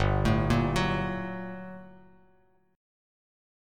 GmM9 chord